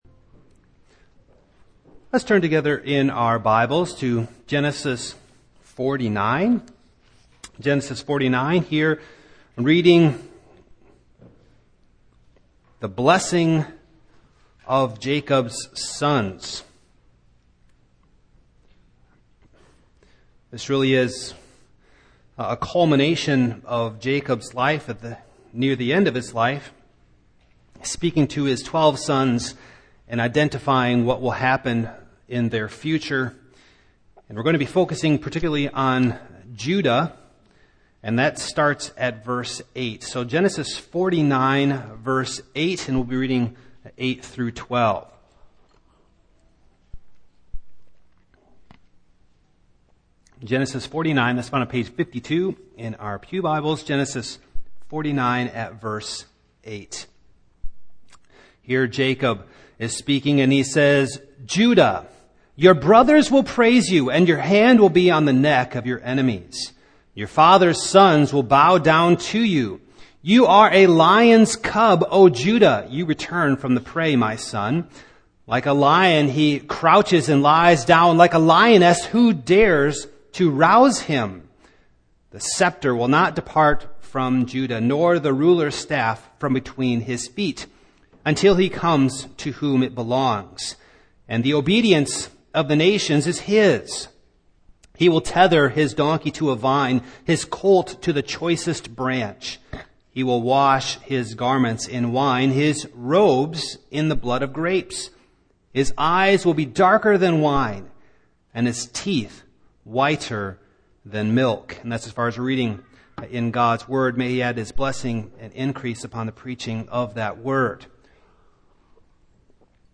Passage: Gen. 49:8-12 Service Type: Morning